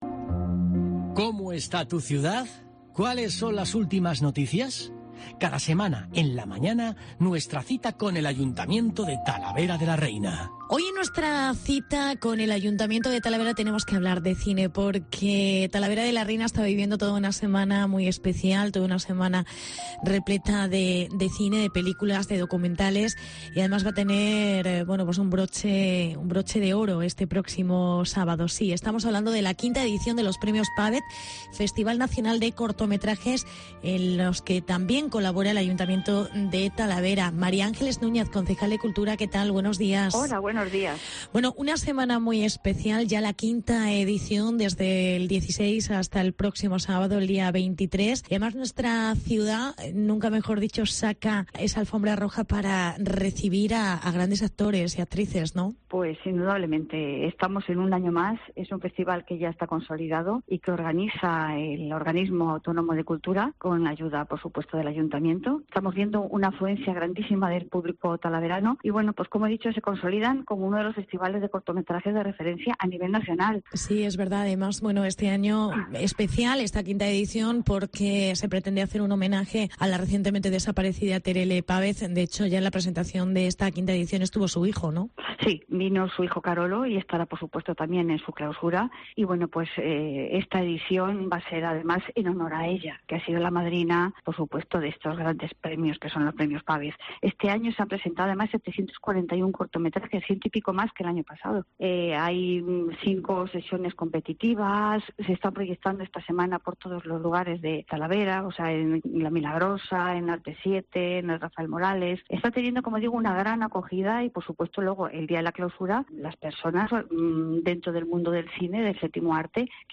Entrevista con la concejal Mª Ángeles Núñez